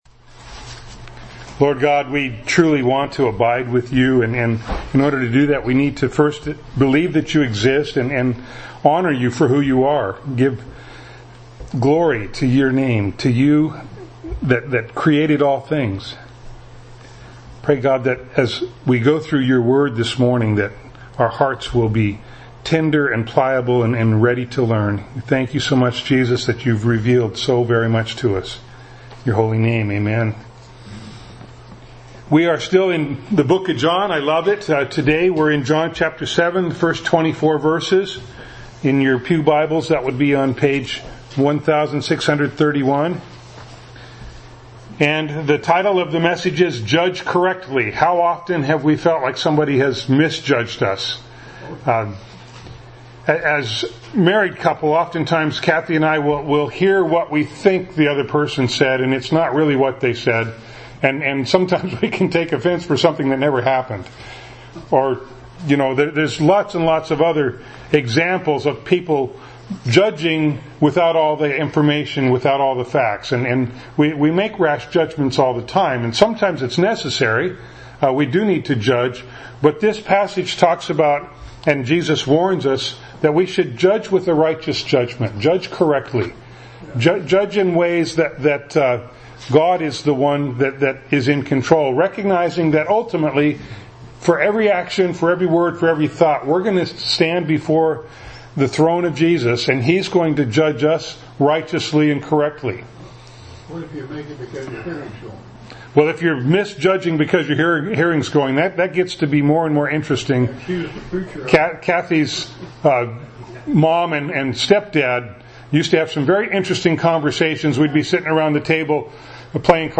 John 7:1-24 Service Type: Sunday Morning Bible Text